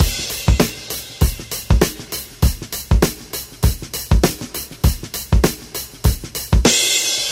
99 Bpm Breakbeat Sample C Key.wav
Free breakbeat - kick tuned to the C note. Loudest frequency: 3318Hz
99-bpm-breakbeat-sample-c-key-NG4.ogg